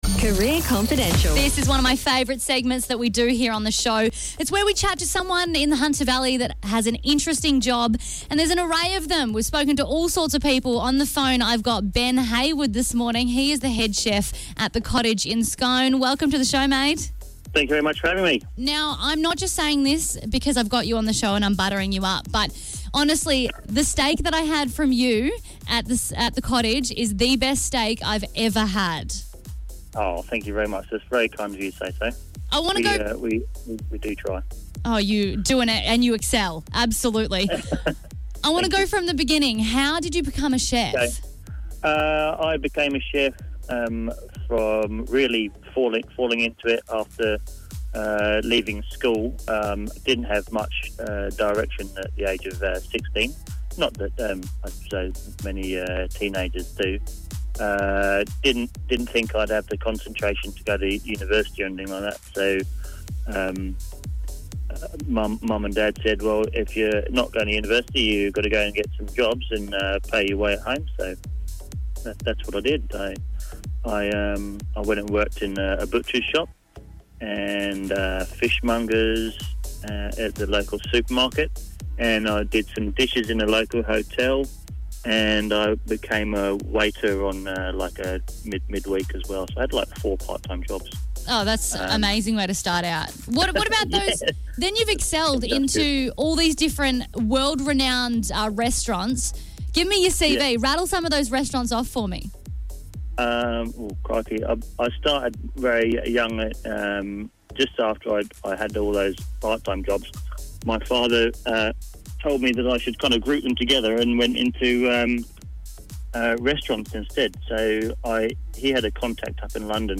Every week, your Power FM Brekky show chat to someone in the Hunter Valley with an interesting job.